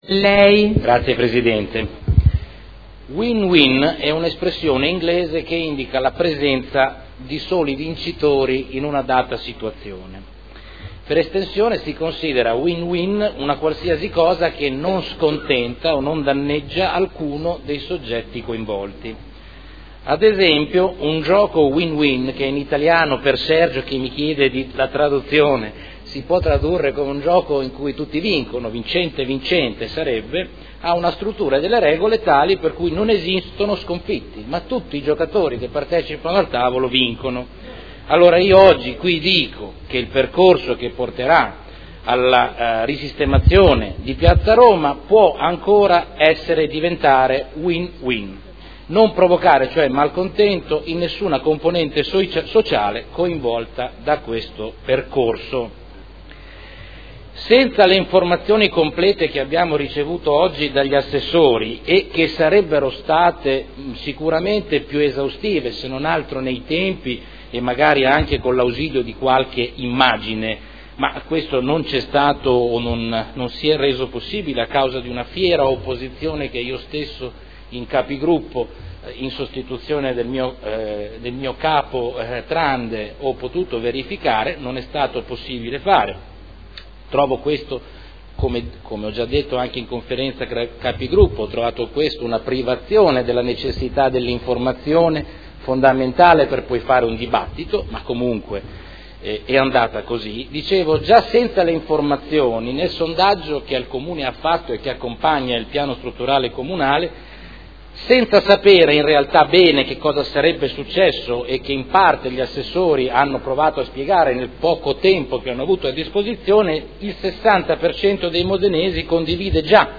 Salvatore Cotrino — Sito Audio Consiglio Comunale